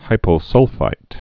(hīpō-sŭlfīt)